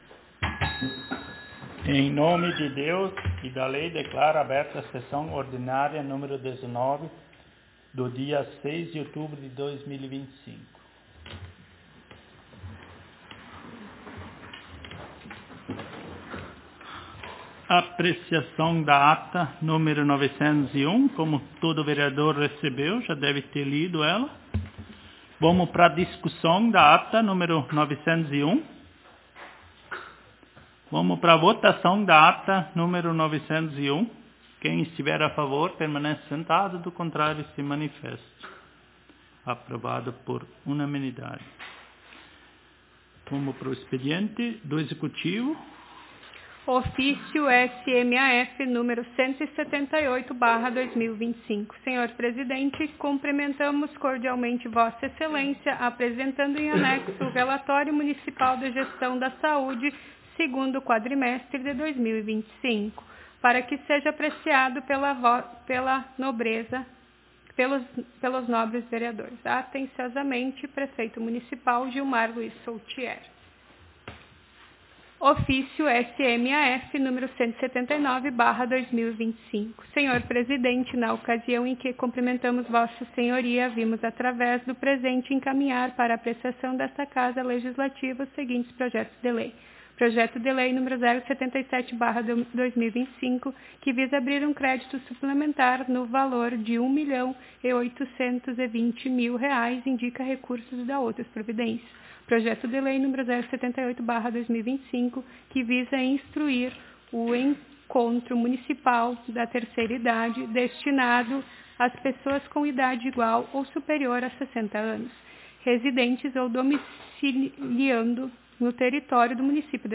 Aos 06 (seis) dia do mês de outubro do ano de 2025 (dois mil e vinte e cinco), na Sala de Sessões da Câmara Municipal de Vereadores de Travesseiro/RS, realizou-se a Décima Nona Sessão Ordinária da Legislatura 2025-2028.